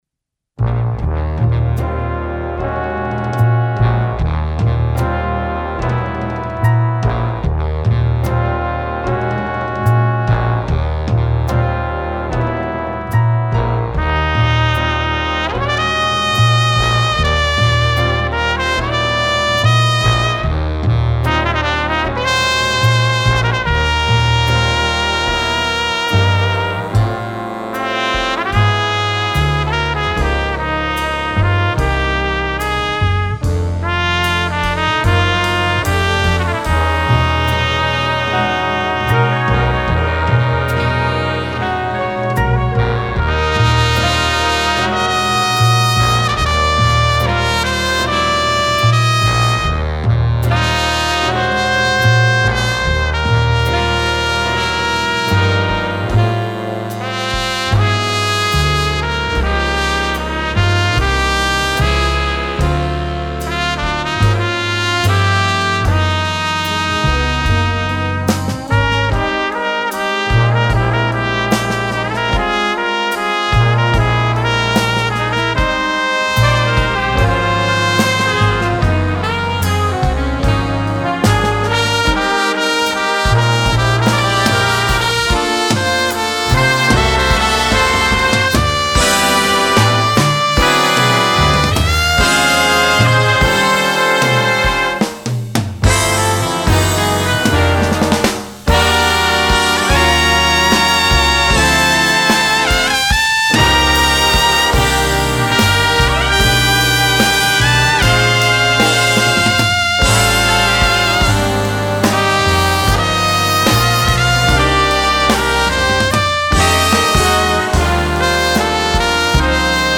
jazz, film/tv, movies